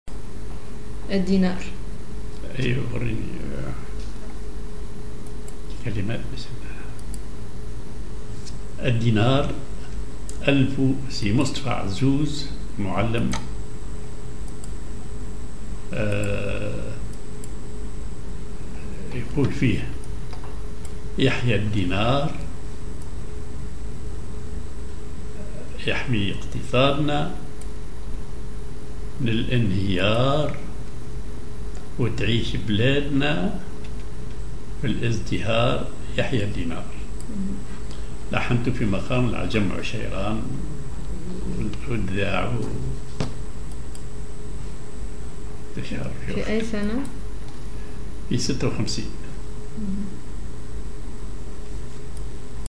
Maqam ar عجم عشيران
genre نشيد